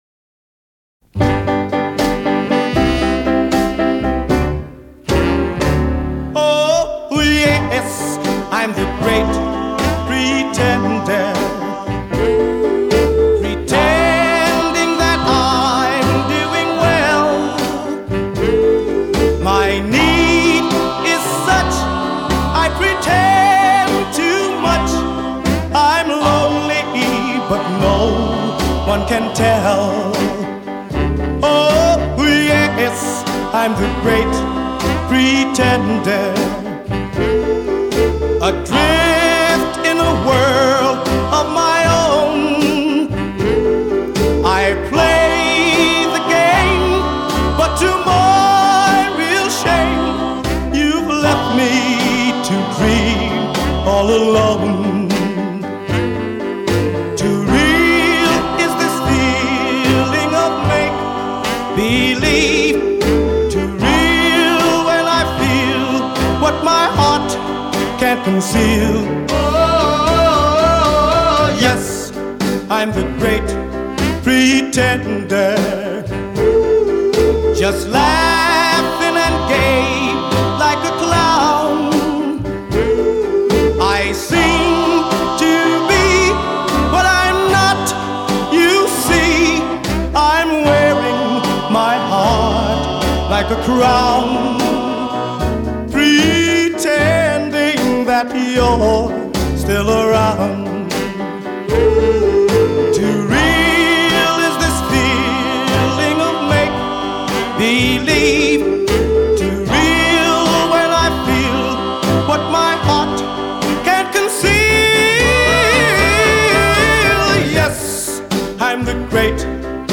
前他们以流行抒情歌曲成功虏获乐迷的心，干净、完美无懈可击的优美和声，让人为之着迷。